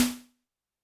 Drums_K4(51).wav